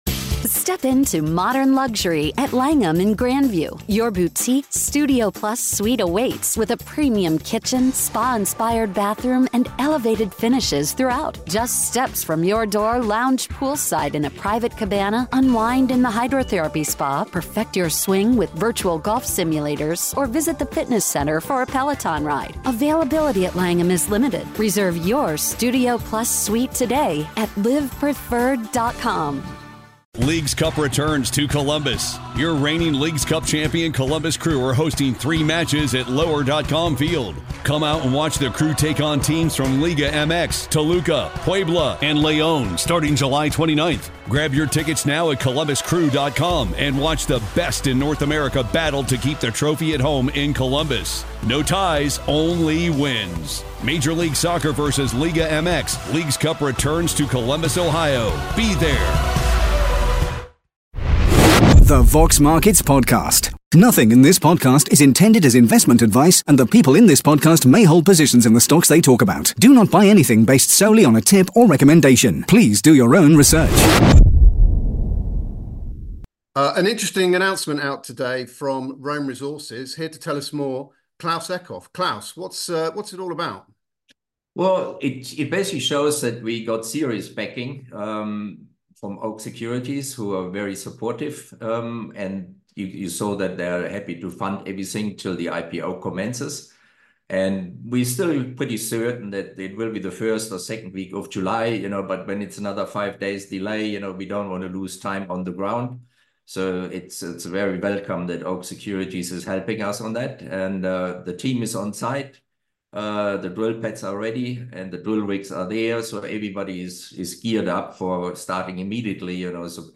The Vox Markets Podcast / Interview